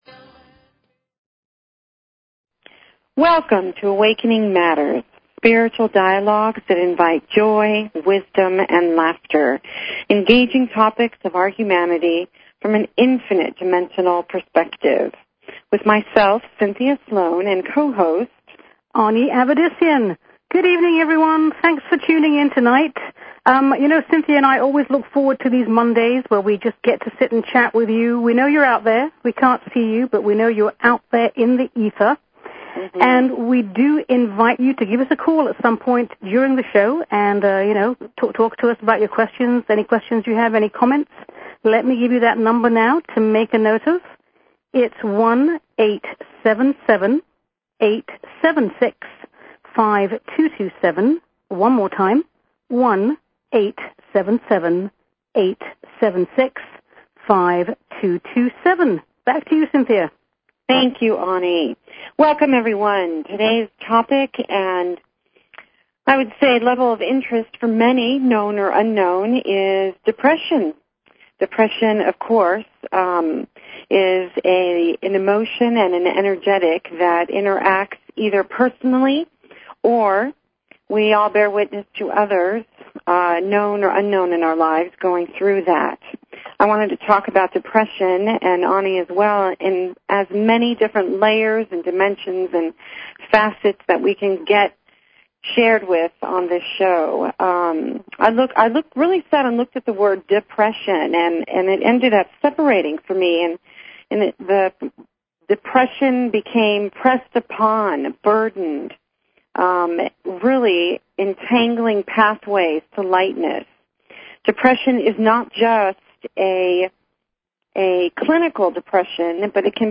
Talk Show Episode, Audio Podcast, Awakening_Matters and Courtesy of BBS Radio on , show guests , about , categorized as
A spiritual dialogue that invites divine wisdom, joy and laughter. Engaging topics of our humanity from an infinite dimensional perspective.